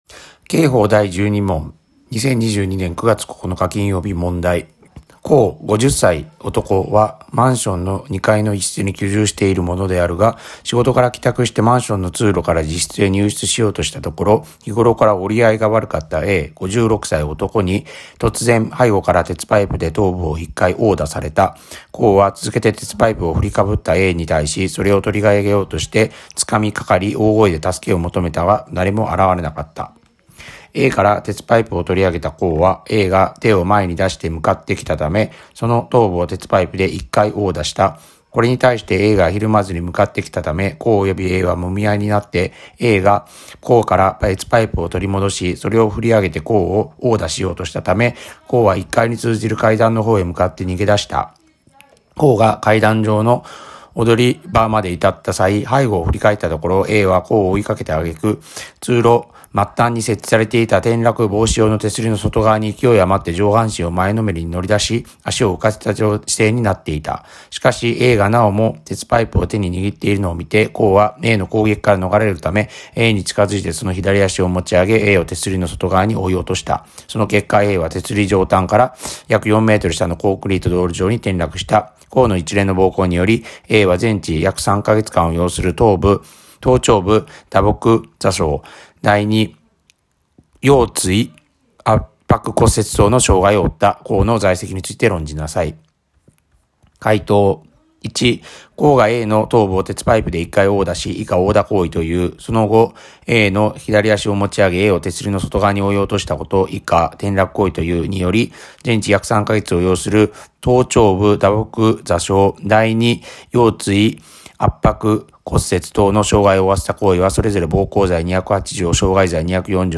問題解答音読